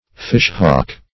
Search Result for " fishhawk" : The Collaborative International Dictionary of English v.0.48: Fishhawk \Fish"hawk`\, n. (Zool.)